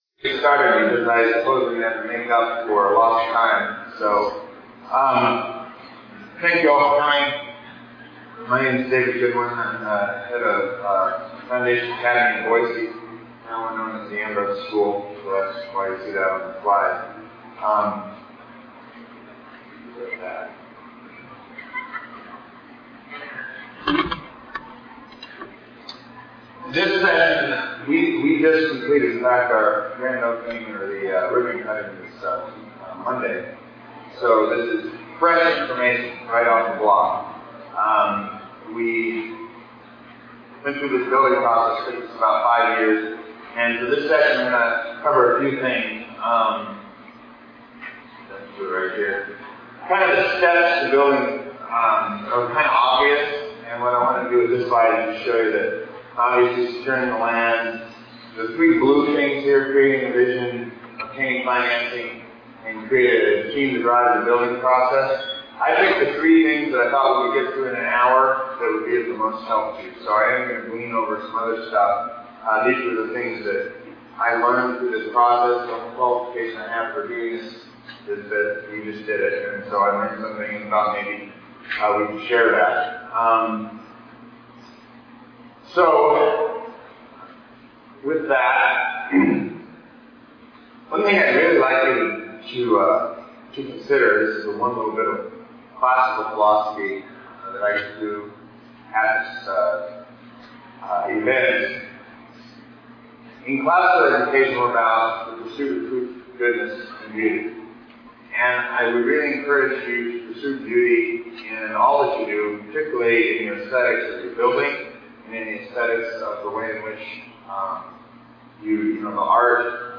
2009 Workshop Talk | 0:59:14 | Budgets & Finance, Employment
The Association of Classical & Christian Schools presents Repairing the Ruins, the ACCS annual conference, copyright ACCS.